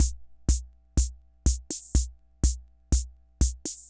Loop9.wav